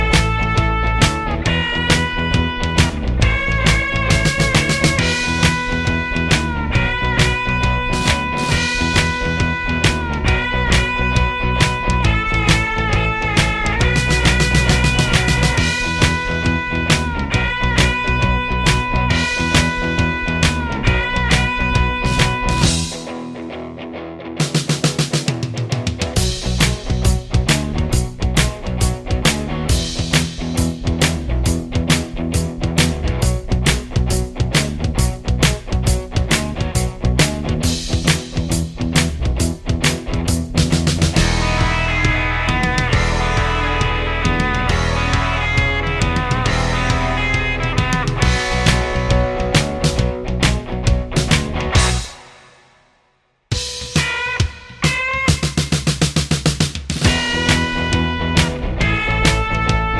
no Backing Vocals Glam Rock 3:20 Buy £1.50